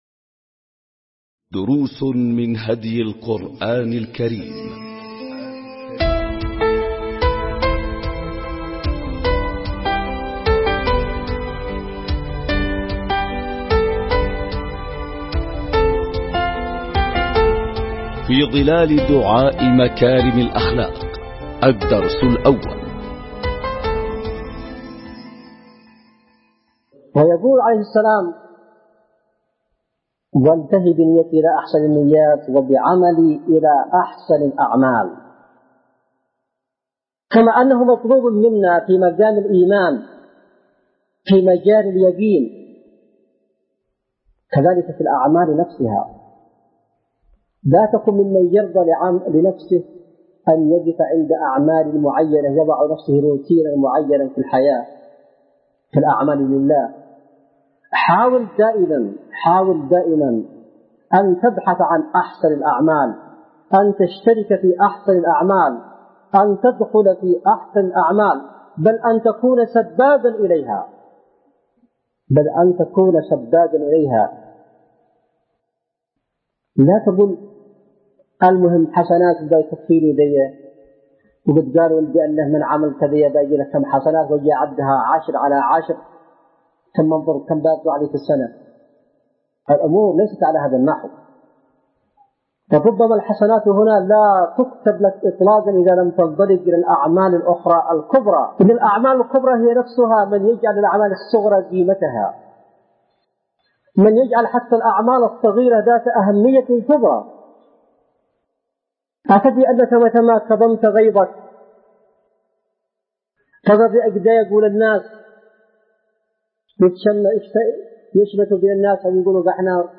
🟢دروس من هدي القرآن الكريم 🔹في ظلال دعاء مكارم الأخلاق – الدرس الأول🔹 ملزمة الأسبوع | اليوم السادس ألقاها السيد / حسين بدرالدين الحوثي بتاريخ 4/2/2002م | اليمن – صعدة | مؤسسة الشهيد زيد علي مصلح